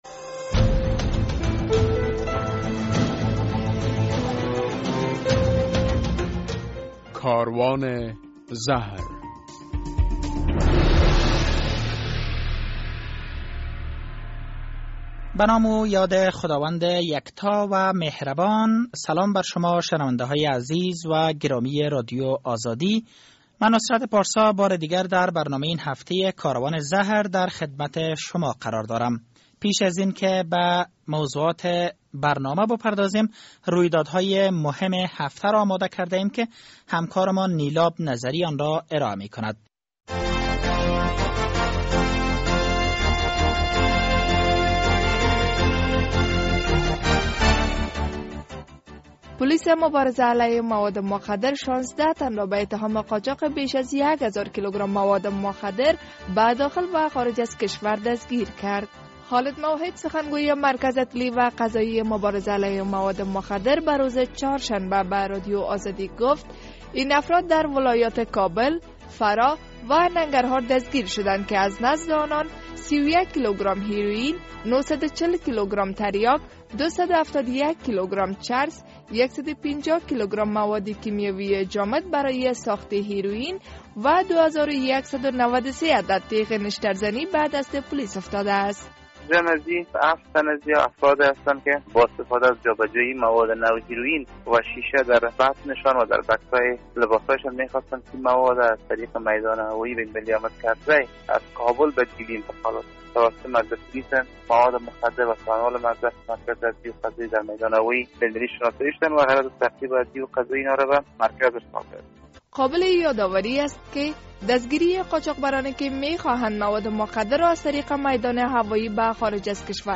در برنامه این هفته کاروان زهر نگاه زود گذر داریم به رویدادهای مهم در بخش مواد مخدر، گزارشی داریم در رابطه به تأکید نامزد قوماندانی نیروهای امریکایی بر مبارزه علیه مواد مخدر، گزارشی را در رابطه به مؤثریت استراتژی چهار ساله مبارزه علیه مواد مخدر می‌شنوید، گزارشی داریم در رابطه به دستآورد‌های پولیس...